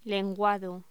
Locución: Lenguado